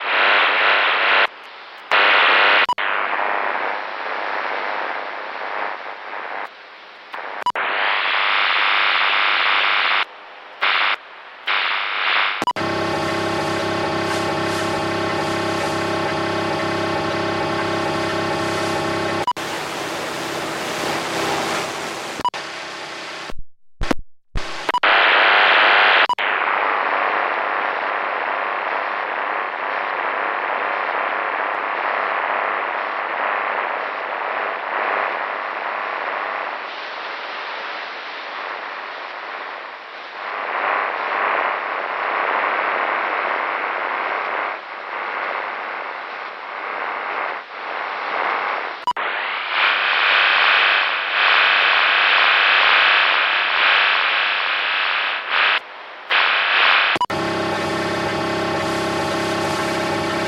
无线电噪音 " RadioNOIZE 3
描述：噪声收音机，从莫斯科的无线电扫描器Icom上录制的。
Tag: 调谐 收音机 噪音